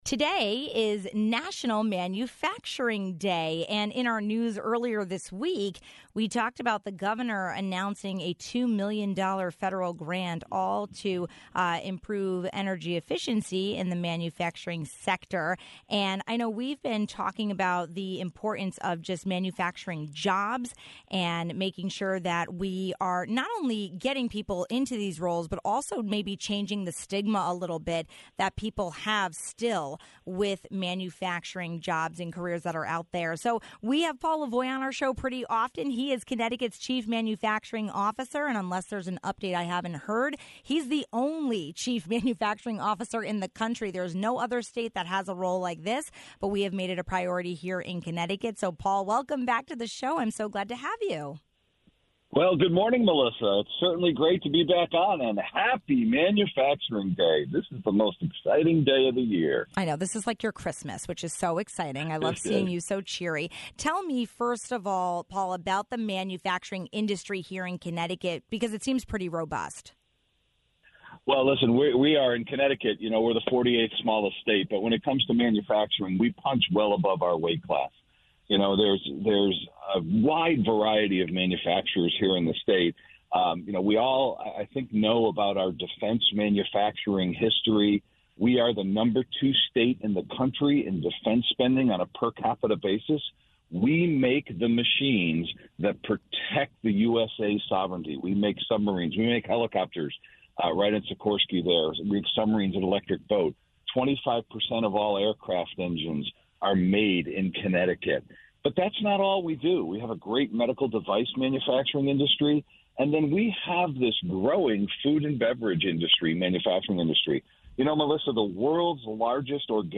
Today is National Manufacturing Day and our state has worked hard to help residents understand the benefits of entering the industry and eliminate false stigmas. We spoke with Connecticut’s Chief Manufacturing Officer, Paul Lavoie.
We get reaction from Senator Ryan Fazio, ranking Republican member of the Energy Committee.